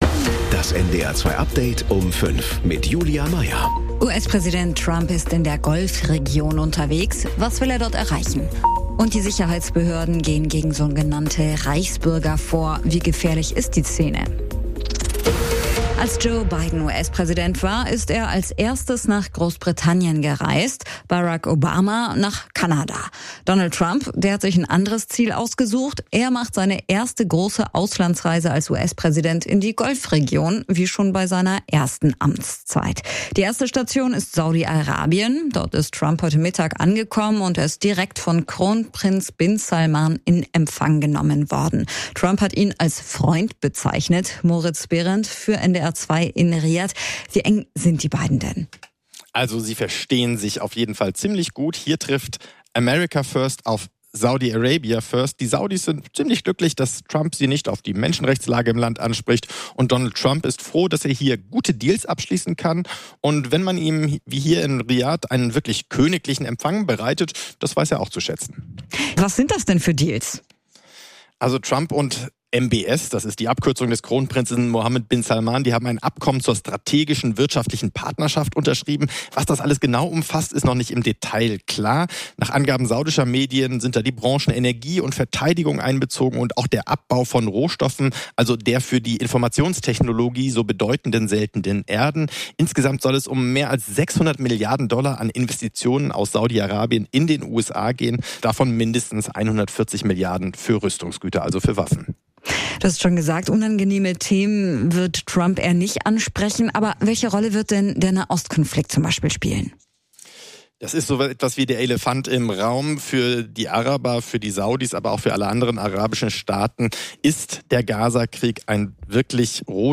… continue reading 108 jaksoa # Kurier Um 5 # Nachrichten # NDR 2